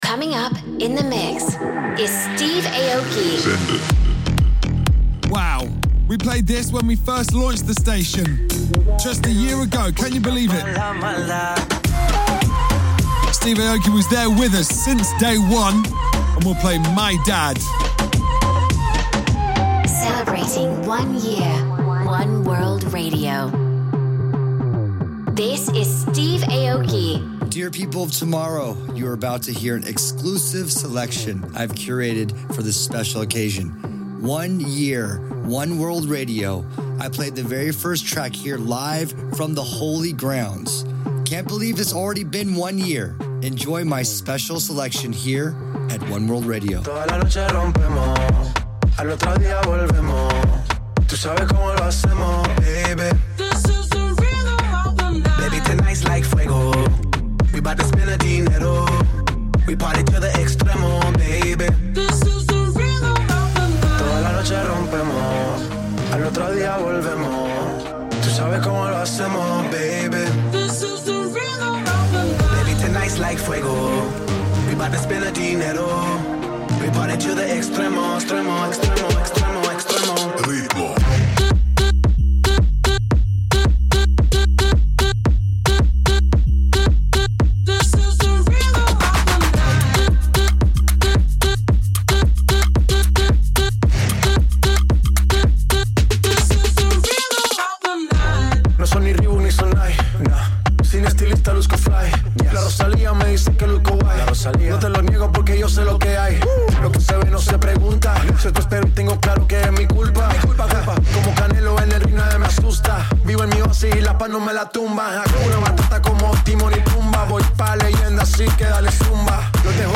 Also find other EDM Livesets, DJ Mixes and